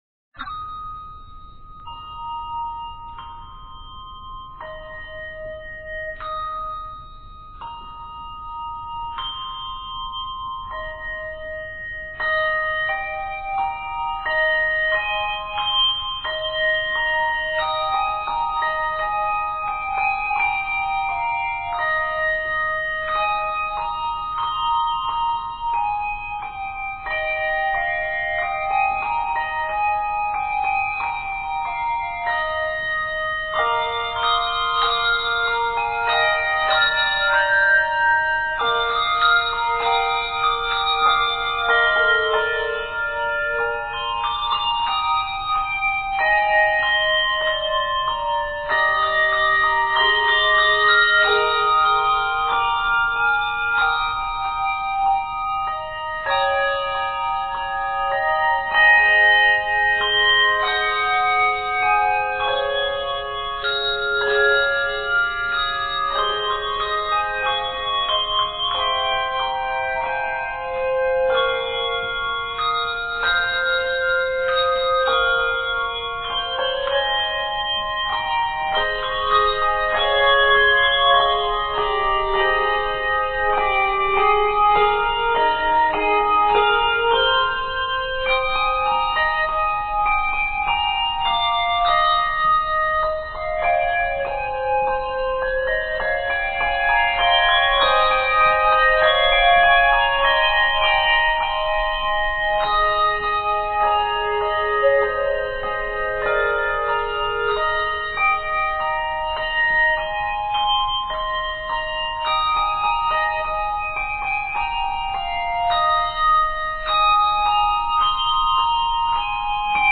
with linear and chordal textures